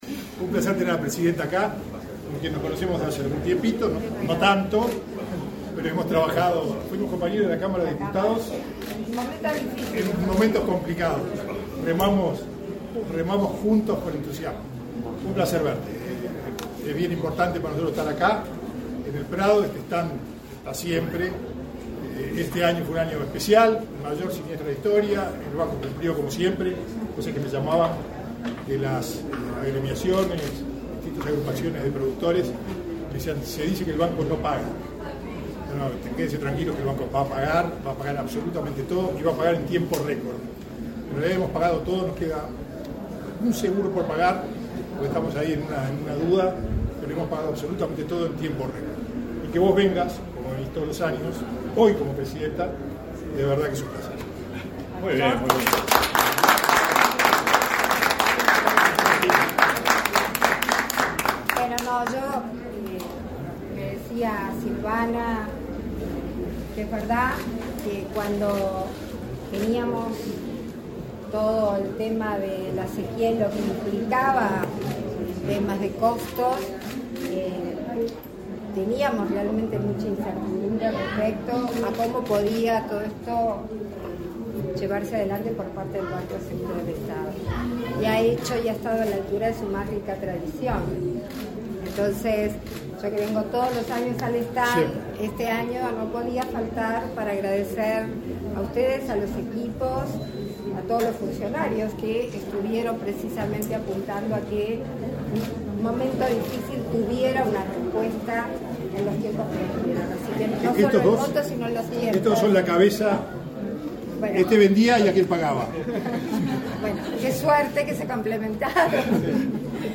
Palabras de la presidenta de la República en ejercicio, Beatriz Argimón, y del presidente del BSE, José Amorín Batlle | Presidencia Uruguay
La presidenta de la República en ejercicio, Beatriz Argimón, visitó, este 13 de setiembre, el stand del Banco de Seguros del Estado (BSE).